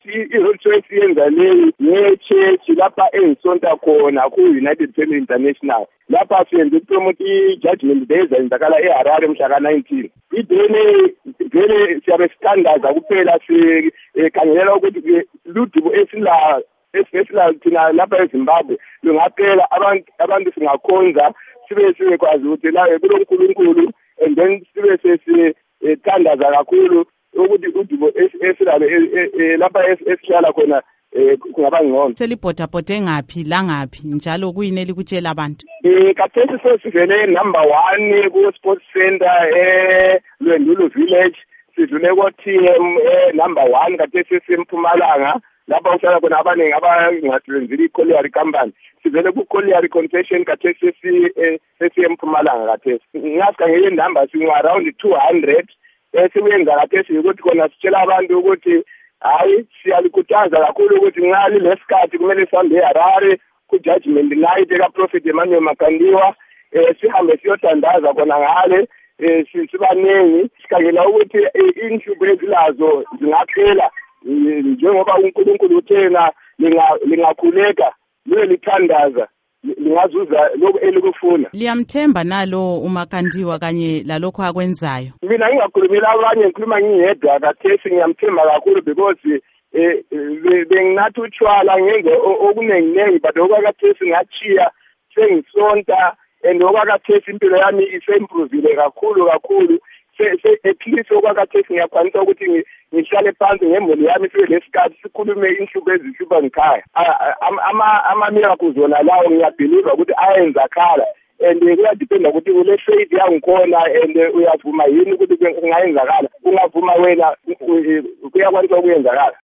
Igxoxo loMnu.